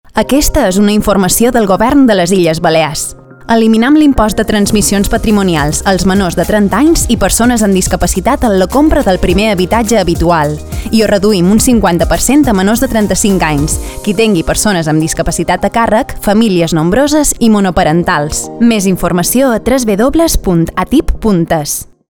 Cuñas
Arxiu Multimedia Cuña 1 Sucesiones (.mp3)